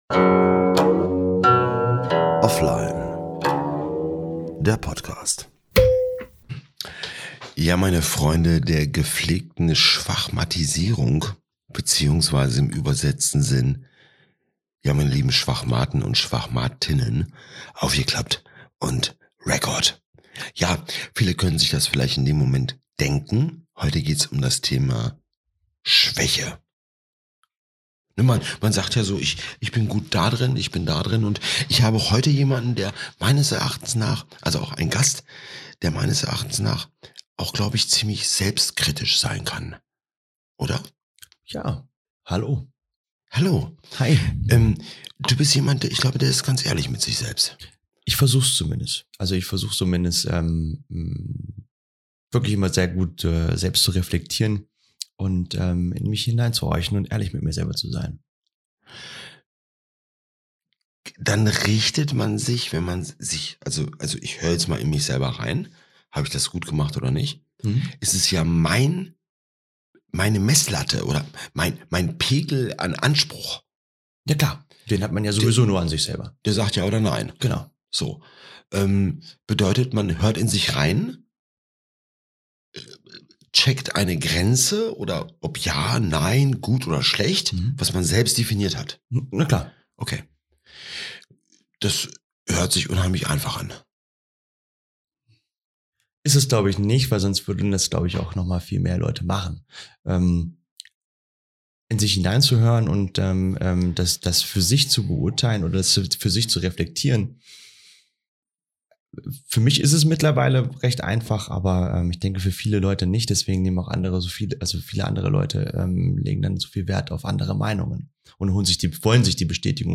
Im Gespräch mit einem Dude der selbstkritisch ist und auch mal